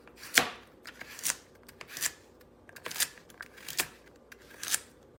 Хруст моркови при кусании, жевание, нарезка и другие звуки в mp3 формате
4. Нарезают морковь ножом